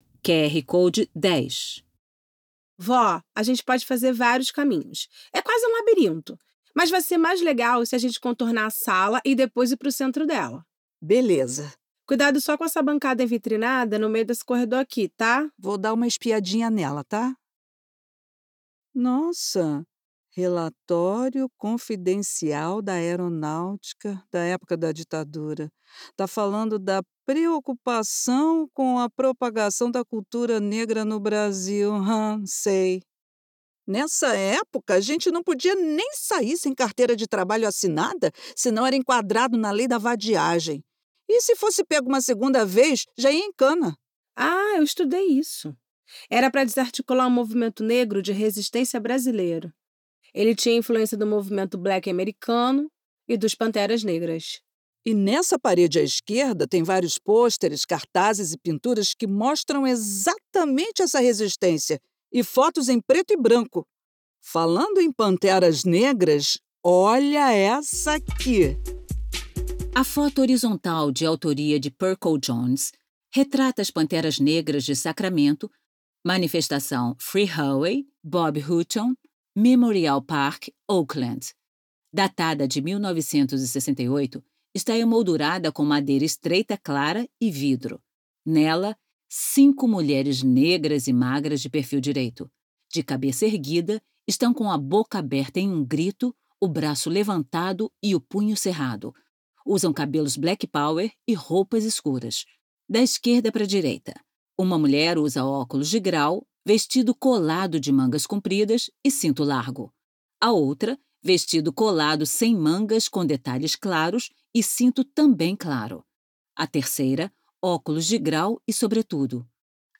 Audiodescrição 10 – Corredor 2